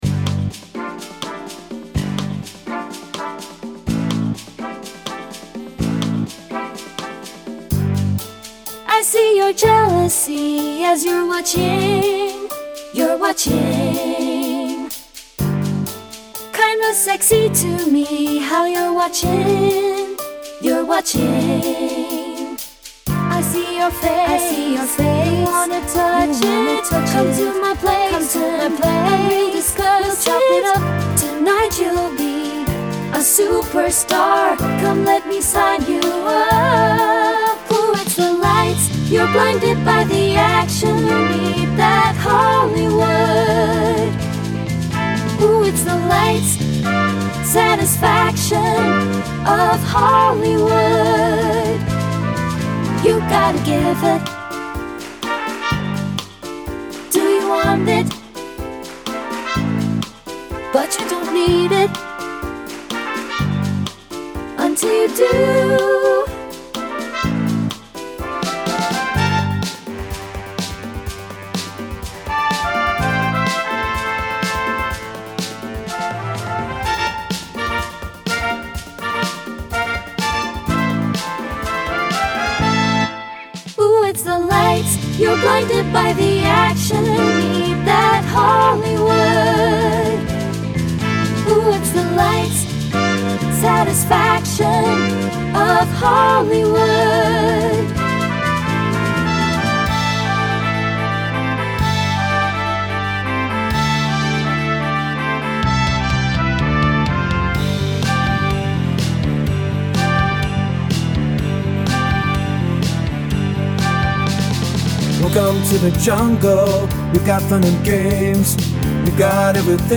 Show Choir Music
Treble/TB